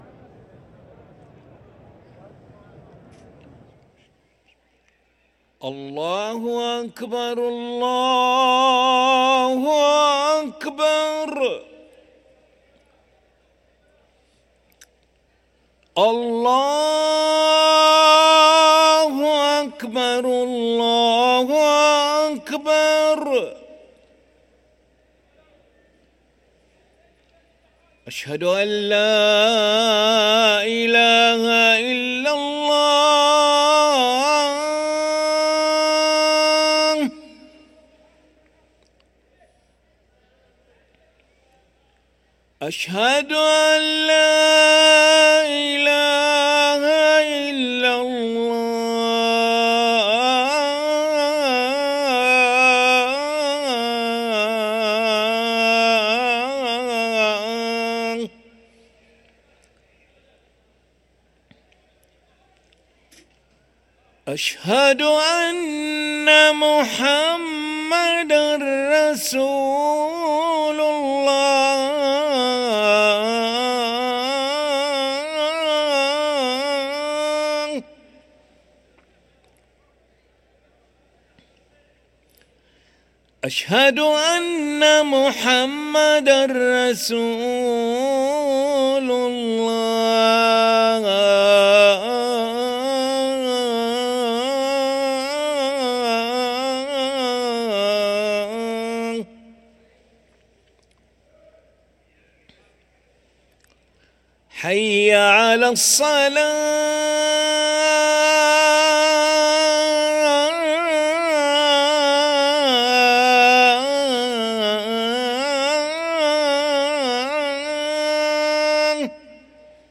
أذان العشاء للمؤذن علي ملا الأحد 18 صفر 1445هـ > ١٤٤٥ 🕋 > ركن الأذان 🕋 > المزيد - تلاوات الحرمين